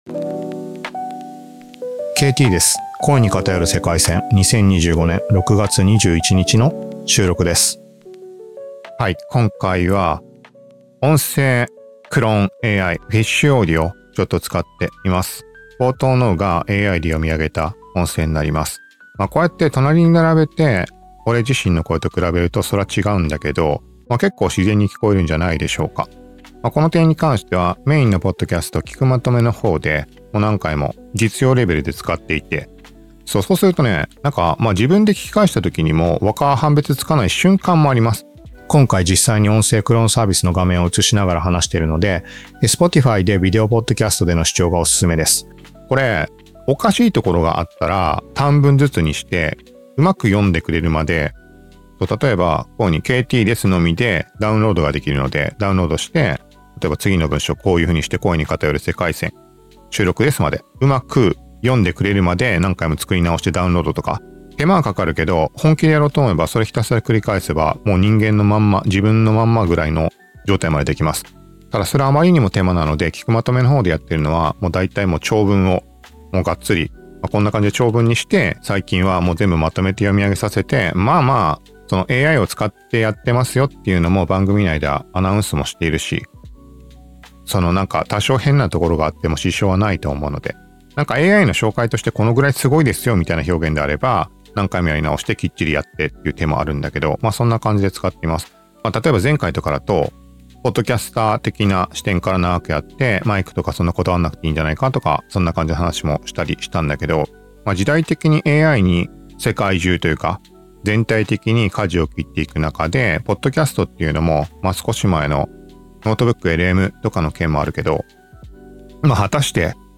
なぜか3分半前後から50秒間ほど、音声がプツプツ途切れて聴きづらいです。